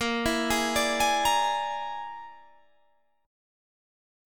A#7b5 Chord